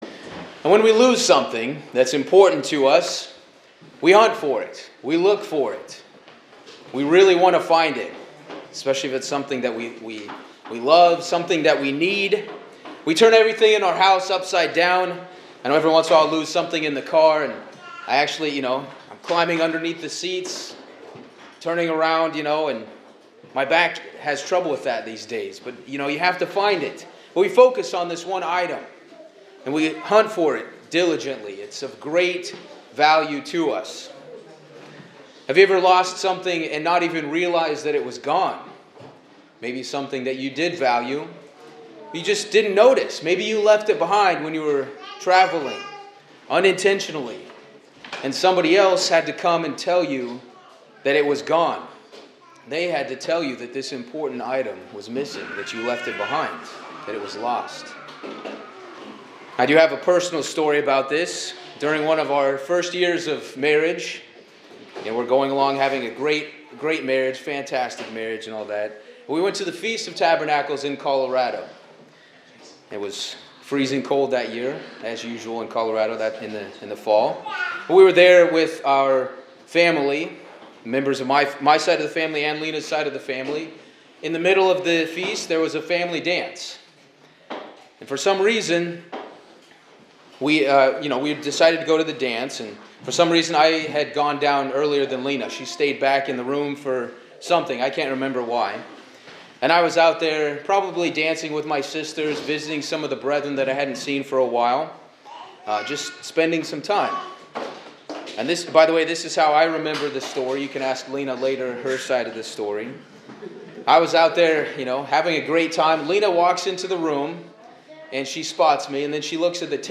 Sermons
Given in Hartford, CT